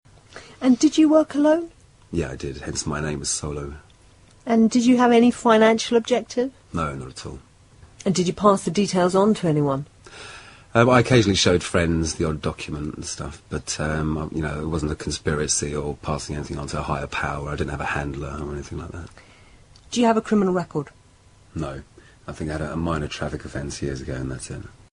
Tags: UFO Hacker Gary Mckinnon Gary Mckinnon interview Hacker interview UK hacker